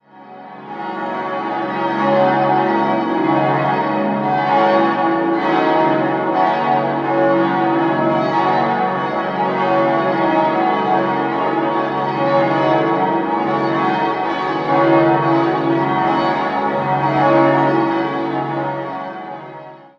Nach massiven Zerstörungen im Zweiten Weltkrieg, bei denen nur der Turm erhalten blieb, erfolgte zwischen 1953 und 1957 der Wiederaufbau. 6-stimmiges Geläut: des'-es'-ges'-as'-b'-des'' Alle Glocken wurden 1966 von der Firma Petit&Edelbrock in Gescher gegossen.